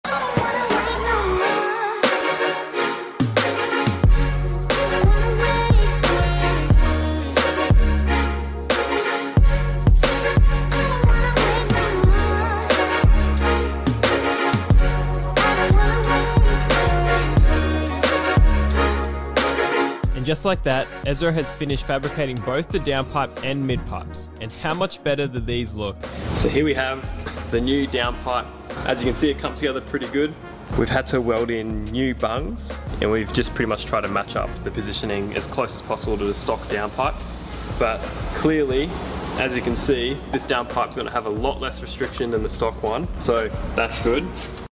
Full custom downpipe and midpipe sound effects free download
Full custom downpipe and midpipe install on the RS3! Car is definitely very loud now 👌🏽 Full length install video and sound clips can be found on my YouTube channel.